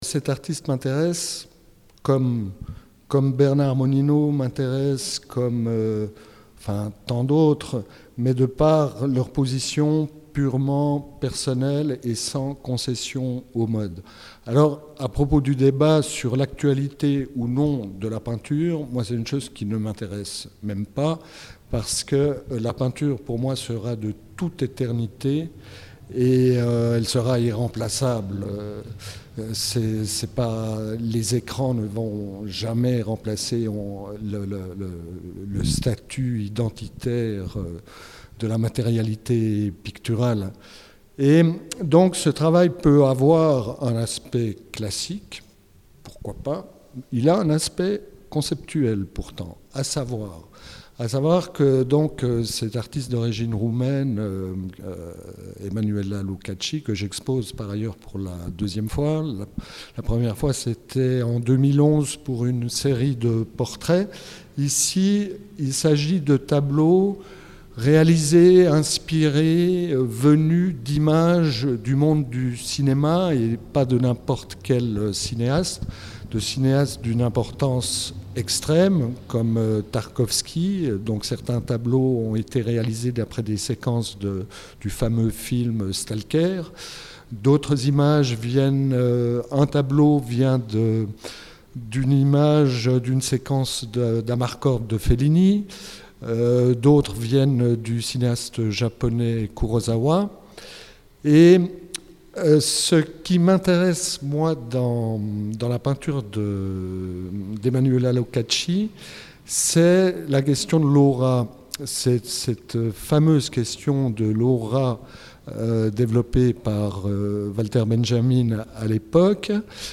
Lors de cet entretien